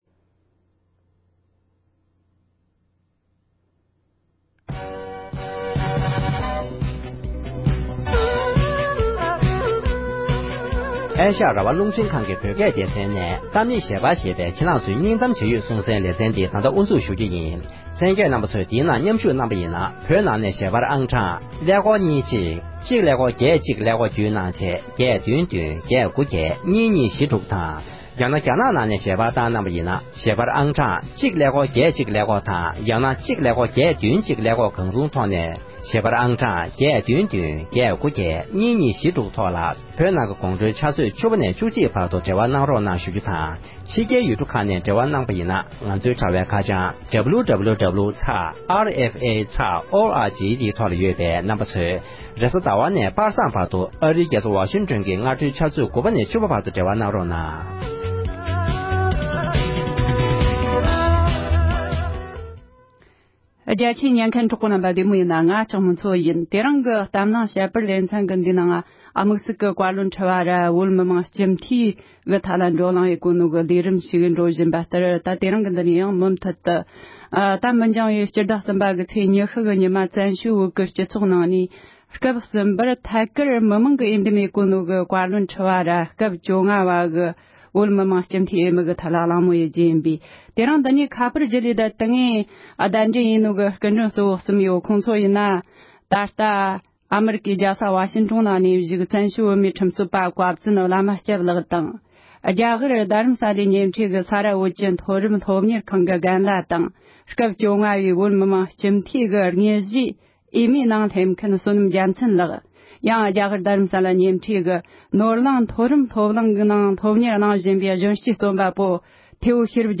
བཀའ་སྤྱིའི་འོས་བསྡུ་སྐོར་གླེང་མོལ།